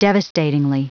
Prononciation du mot devastatingly en anglais (fichier audio)
Prononciation du mot : devastatingly